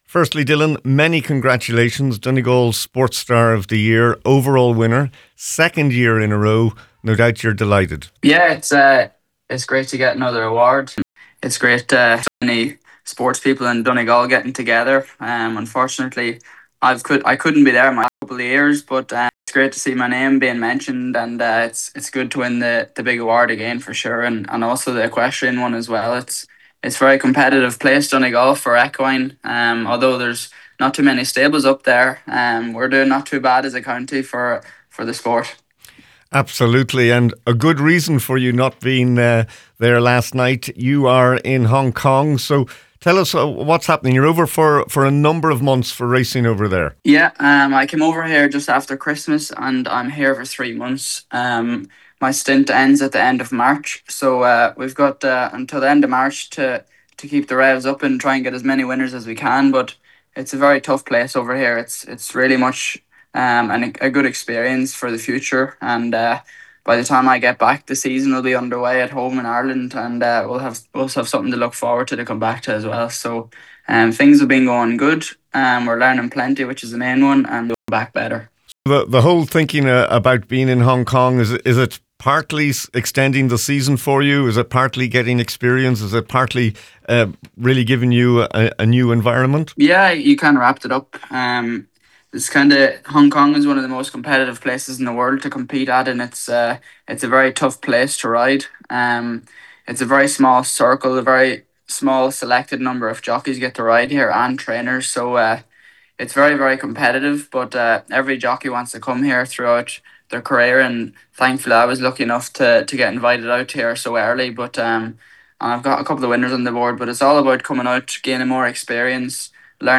On Saturday Sport, Dylan Browne McMonagle spoke